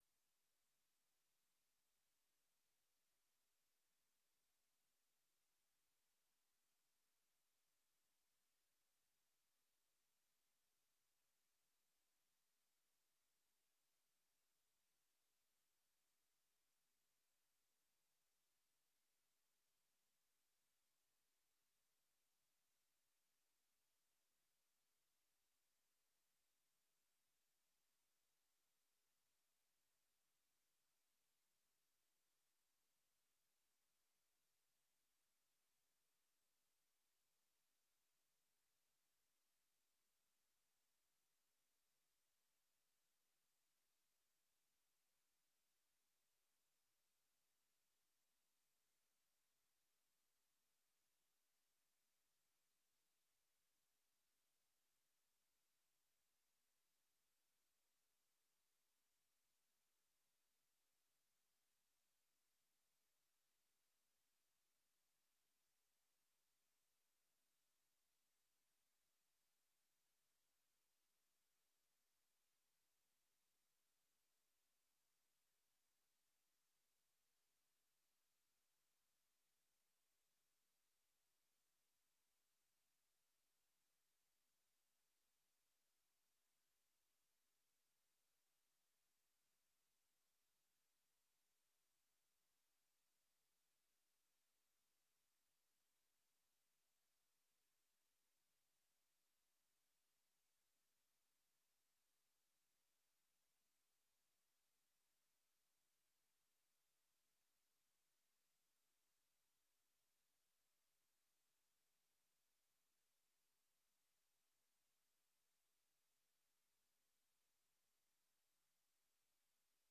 Gemeenteraad Tubbergen 28 mei 2024 19:30:00, Gemeente Tubbergen
Download de volledige audio van deze vergadering